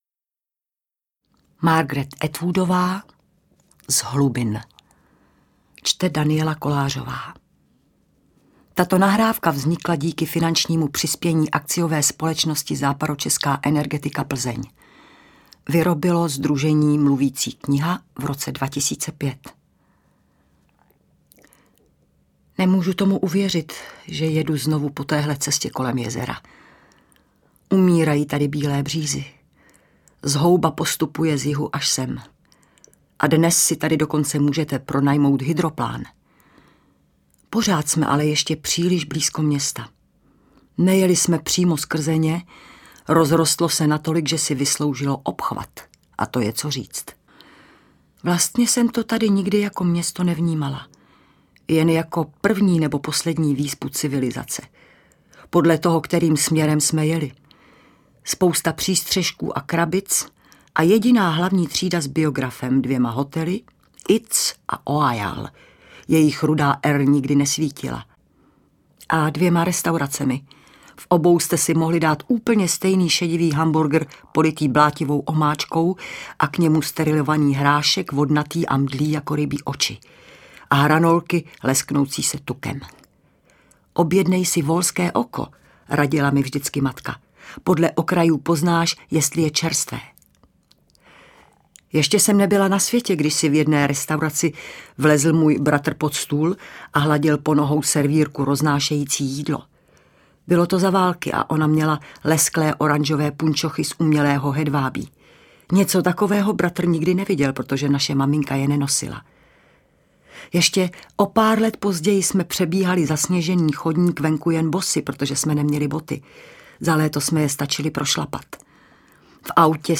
Čte: Daniela Kolářová